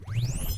Sound Buttons: Sound Buttons View : Laser Charge
chargeup.mp3